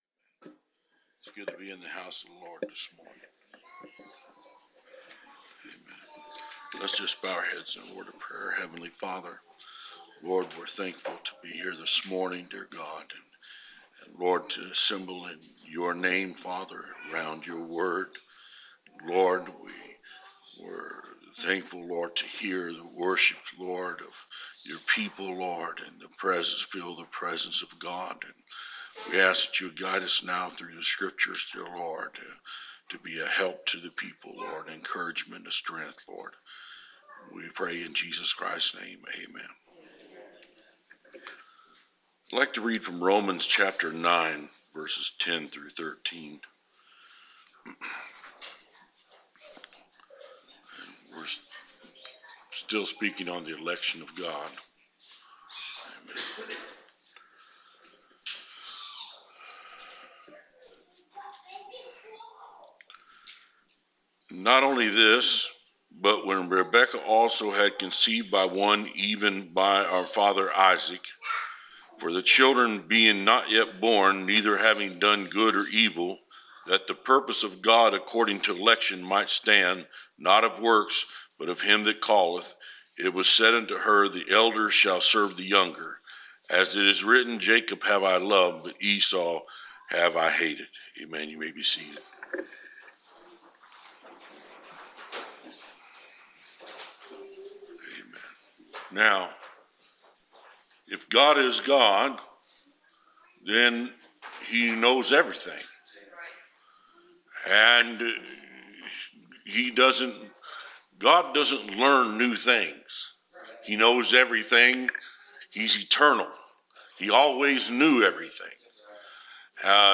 Preached